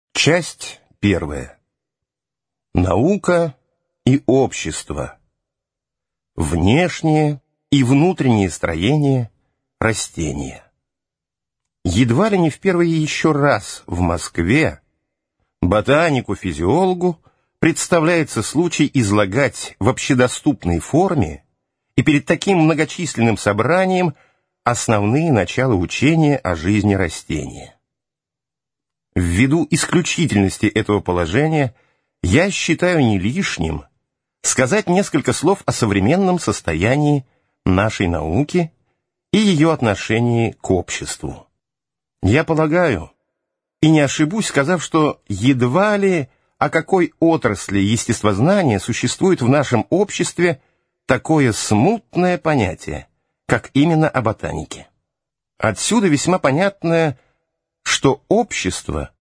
Аудиокнига Жизнь растений | Библиотека аудиокниг